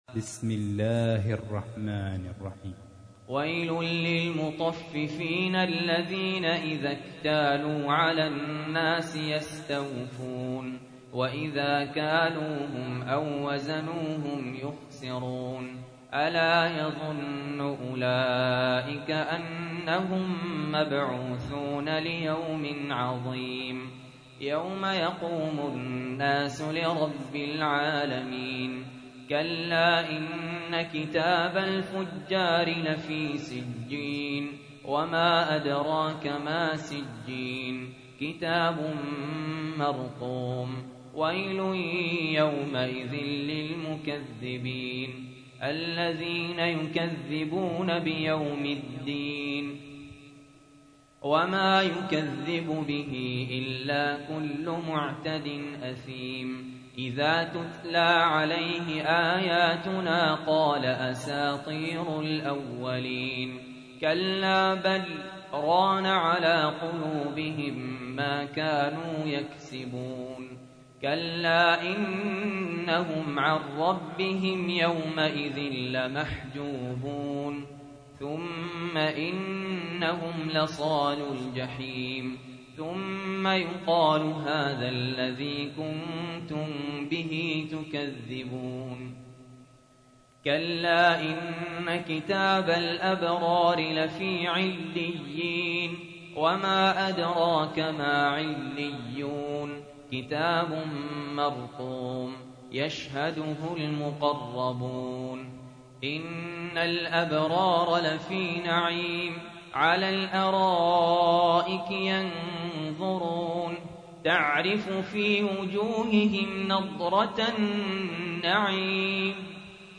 تحميل : 83. سورة المطففين / القارئ سهل ياسين / القرآن الكريم / موقع يا حسين